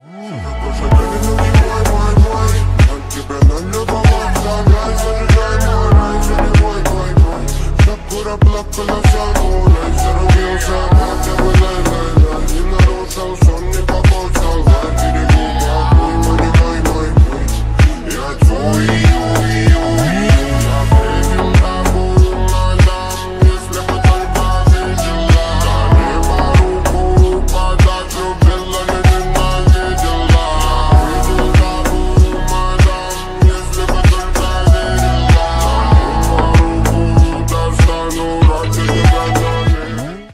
Ремикс
грустные # клубные